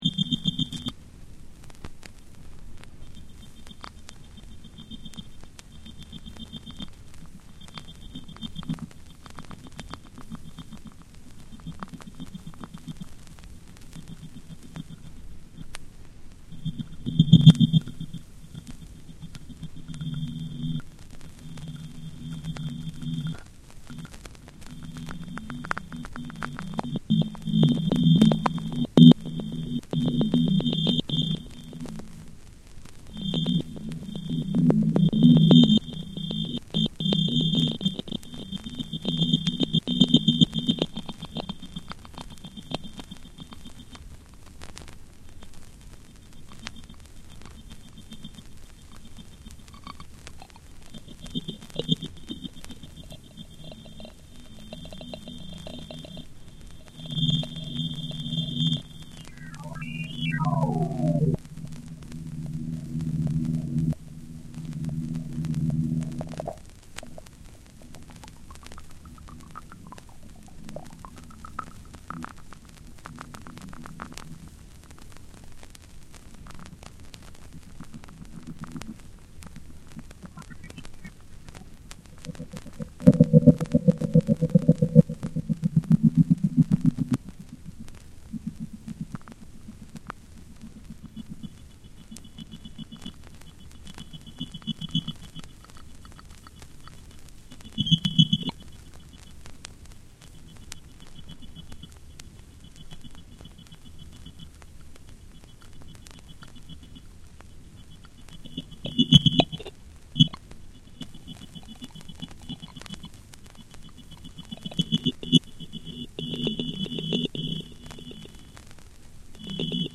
JAPANESE / NEW AGE